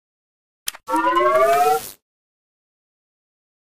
gauss_unjam.ogg